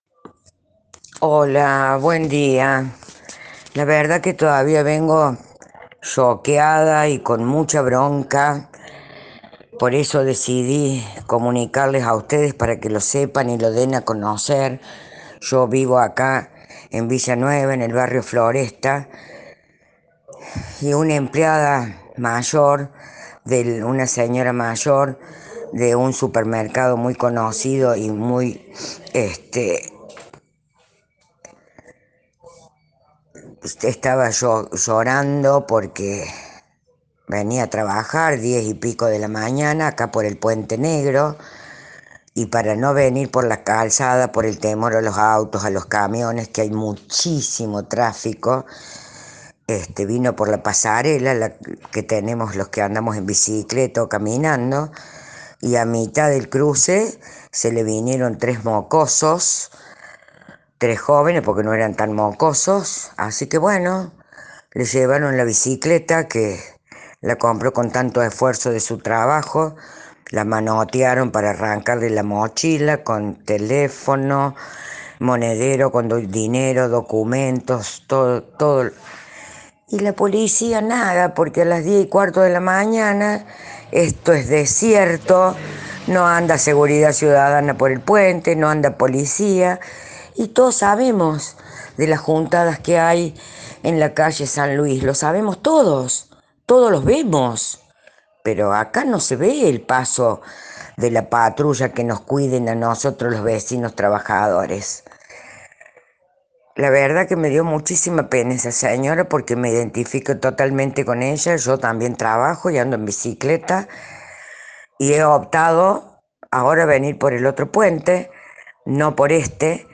AUDIO – Oyente de La Mañana Informal